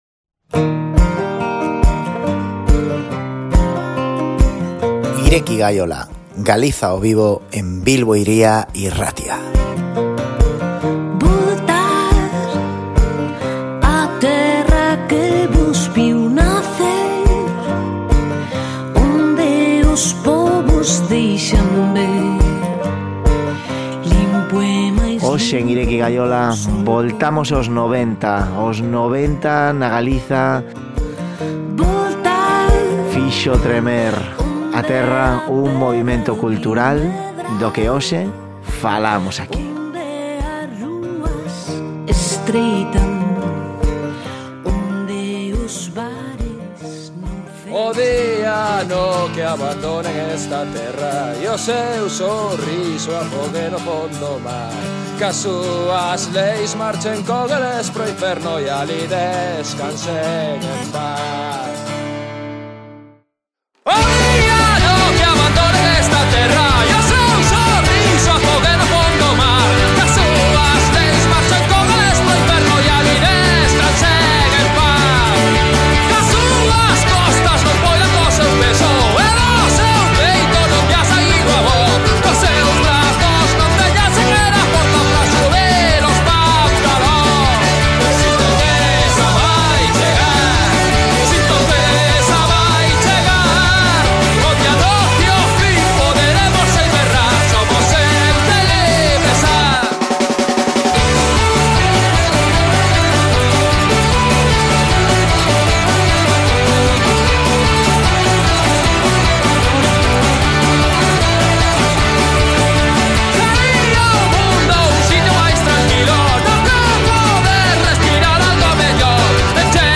no programa de hoxe temos a dous convidados e amigos de Ireki Gaiola